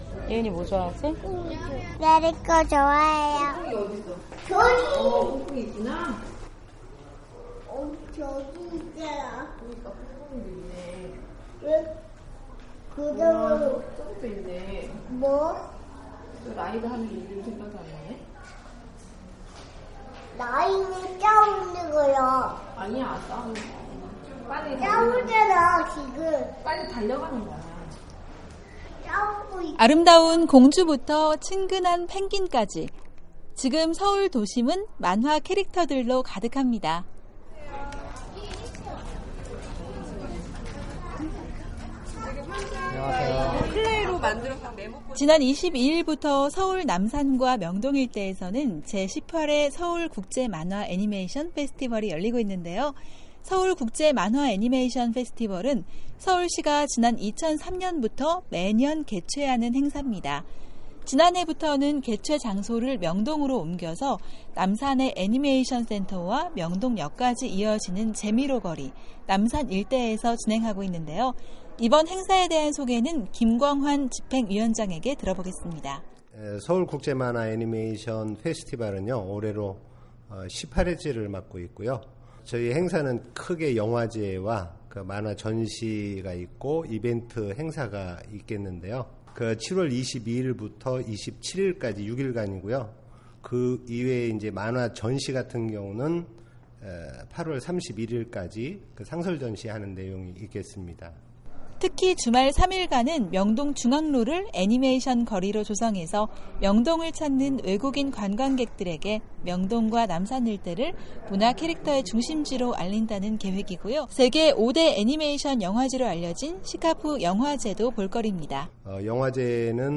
서울 국제 만화 페스티벌 현장에서